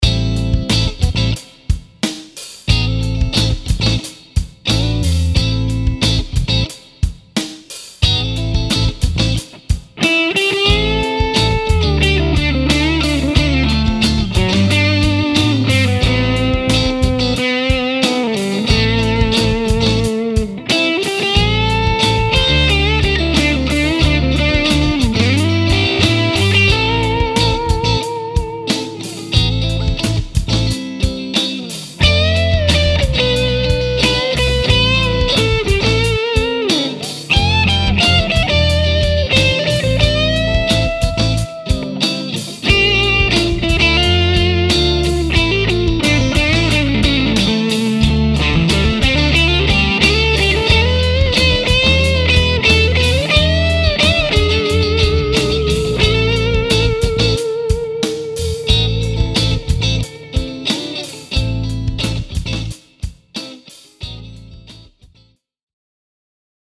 This clip is part of a new song idea I’ve been playing around with. Setup is pretty much the same as above, but for the rhythm, I’m running Strat into my Kasha overdrive pedal to get a jangly, crisp tone. The lead is Goldie plugged straight into my VRX22. I did mix and do a simple master on the recording, but the guitars were all recorded raw, with no EQ. In my DAW, I added some reverb to both parts and a touch of delay to the lead, but that’s it.